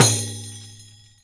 RIQ 1A.WAV